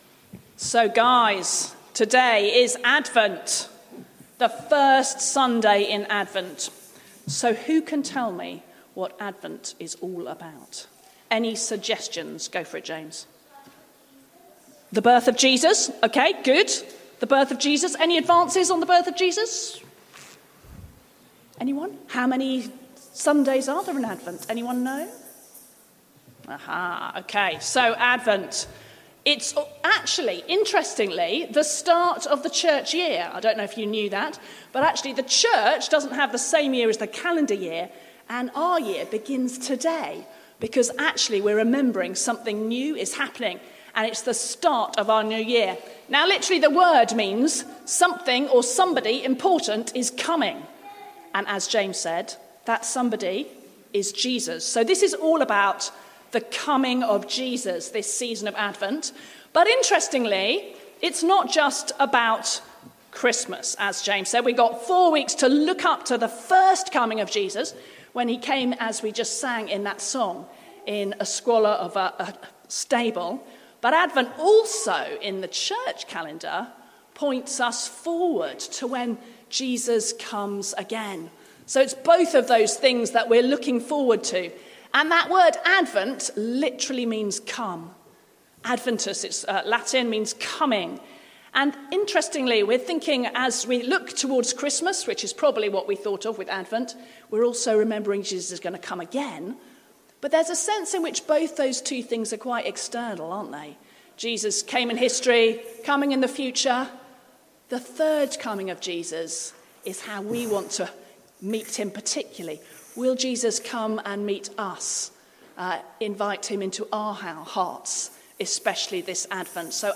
Introducing Advent talk